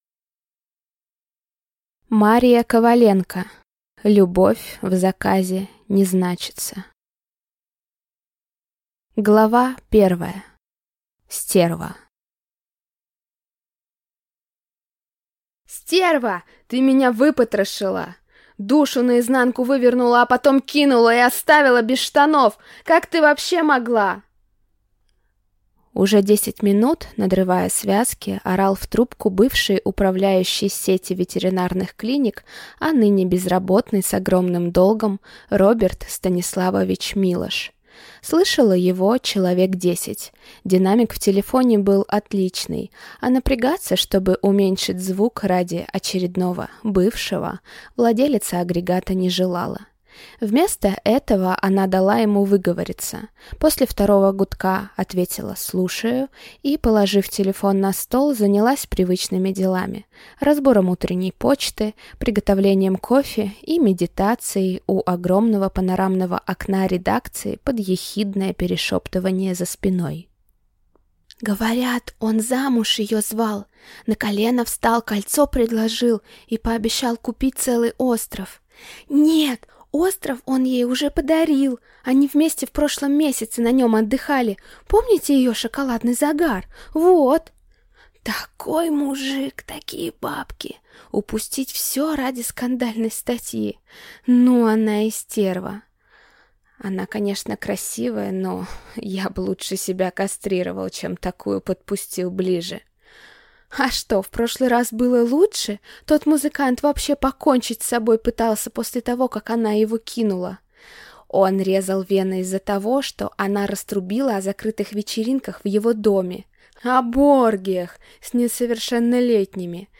Аудиокнига Любовь в заказе не значится | Библиотека аудиокниг
Прослушать и бесплатно скачать фрагмент аудиокниги